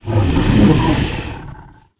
c_hydra_hit3.wav